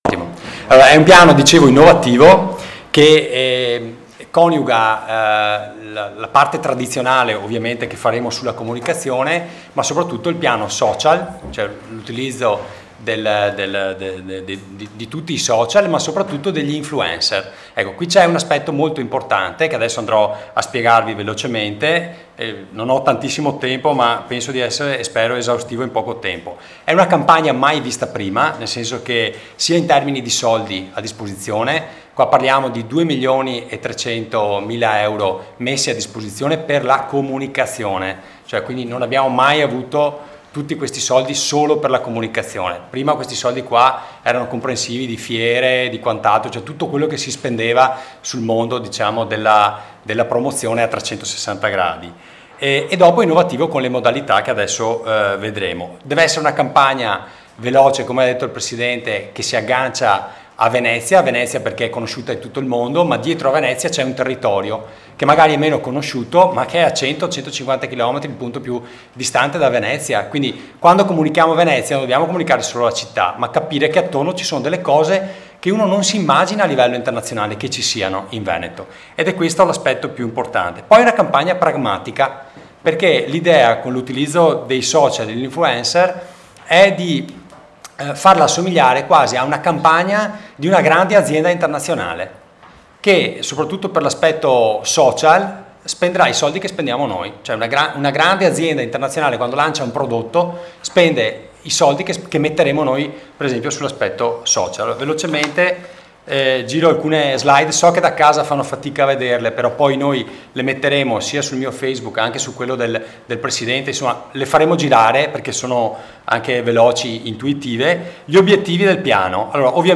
L’INTERVENTO DEL ASSESORE REGIONALE AL TURISMO FEDERICO CANER